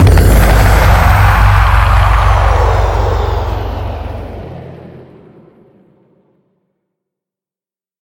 minecraft / sounds / mob / wither / death.ogg
death.ogg